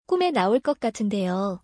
クメ ナオ コッ カトゥンデヨ